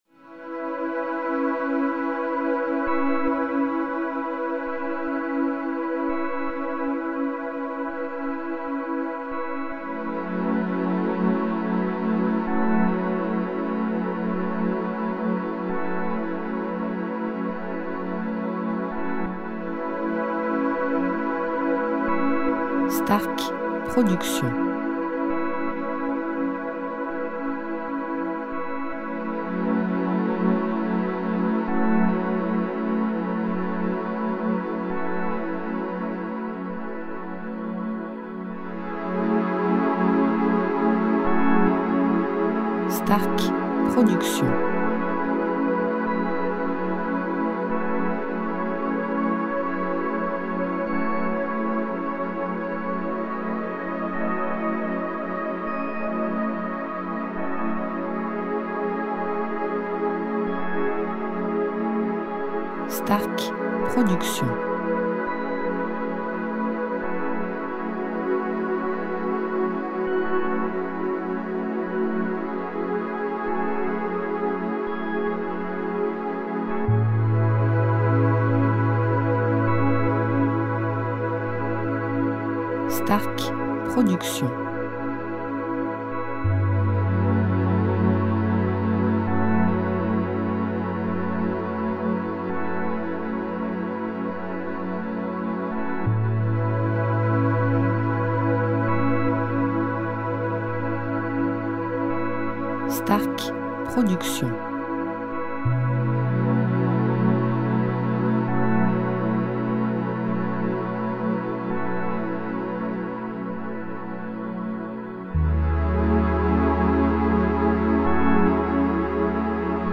style Sophrologie Méditation Relaxant durée 1 heure